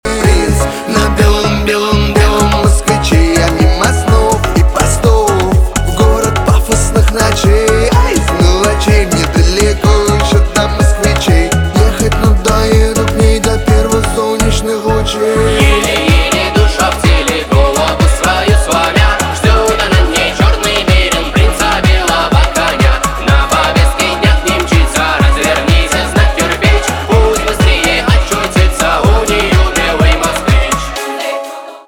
поп
битовые
качающие